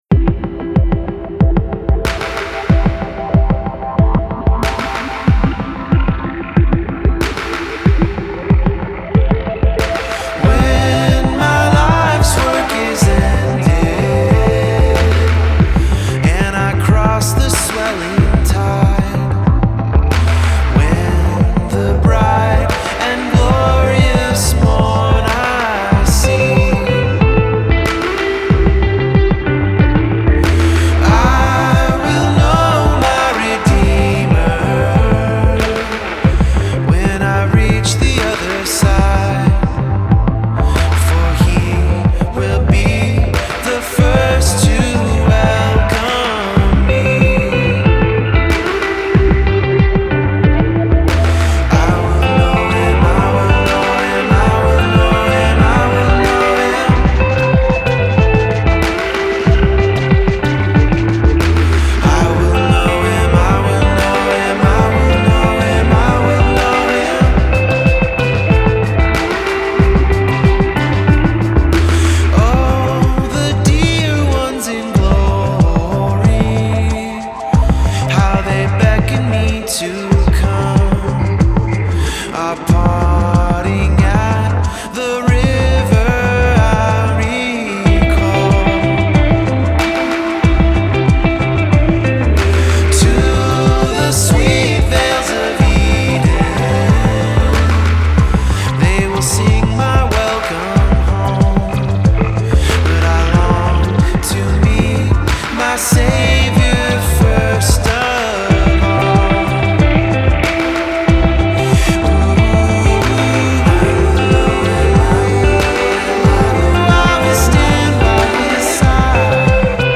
Genre: Alternative, Indie Rock